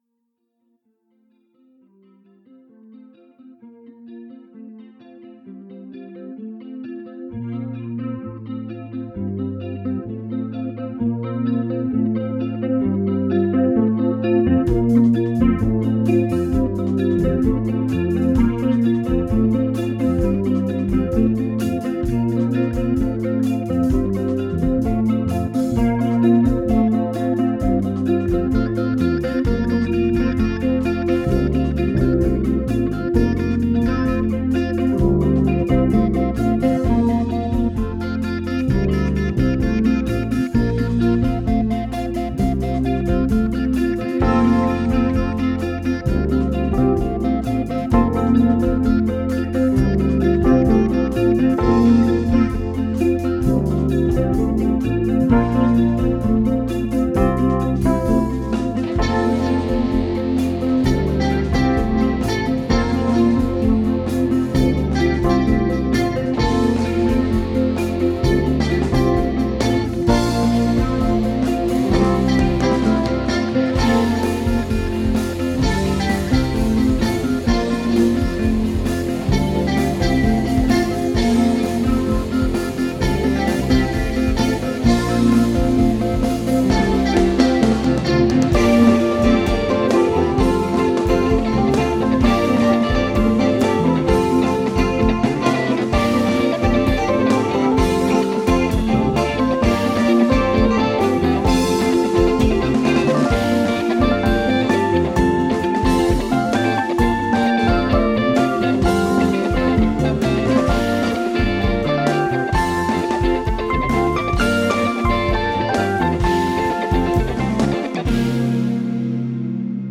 Some old demo tracks (supposed to be gathered in our second demo, “No One Came Back”), reloaded with a couple of changes: new drums tracks, new mix and a few edits from 2015 – still to be recorded properly with a click one day though!
• Bass Guitar: Fender Jazz Bass (with Roland V-Bass for FX).
• Drums: Roland V-Drums triggering the Addictive Drums plug-in, by XLN Audio.